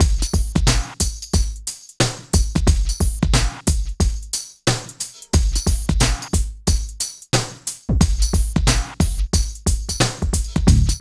85 DRUM LP-R.wav